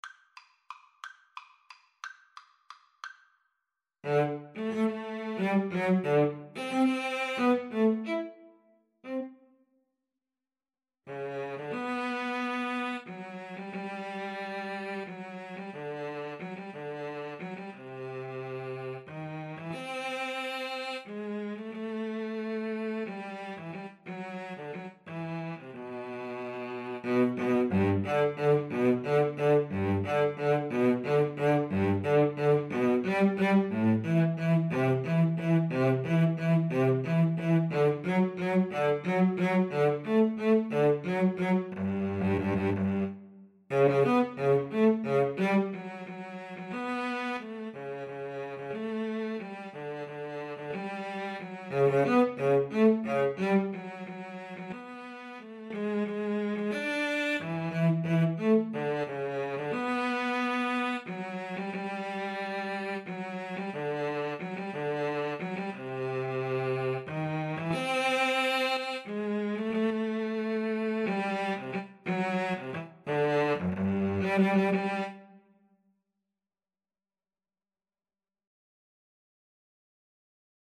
Free Sheet music for Cello Duet
G major (Sounding Pitch) (View more G major Music for Cello Duet )
~ = 180 Tempo di Valse
3/4 (View more 3/4 Music)